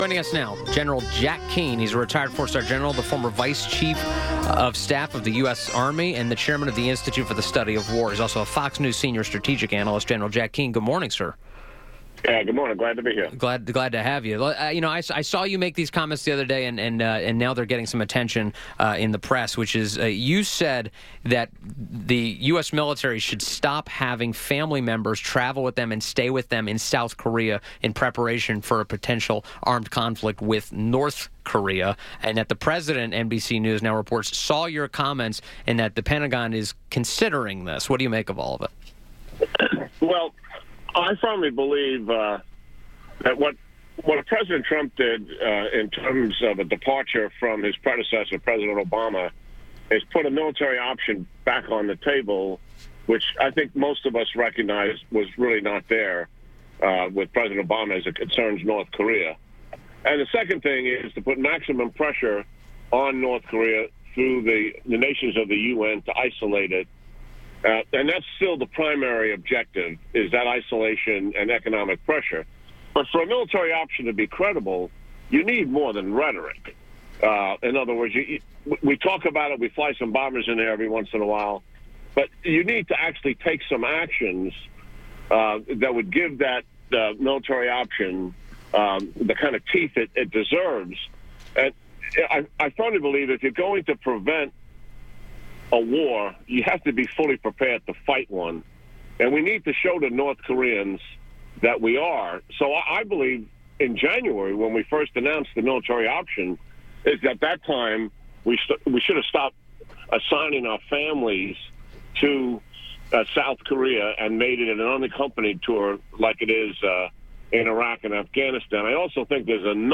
WMAL Interview - GENERAL JACK KEANE - 02-05-18
INTERVIEW -- GEN. JACK KEANE - retired 4 star general, former Vice Chief of Staff of the US Army, the chairman of the Institute for the Study of War and Fox News Senior Strategic Analyst.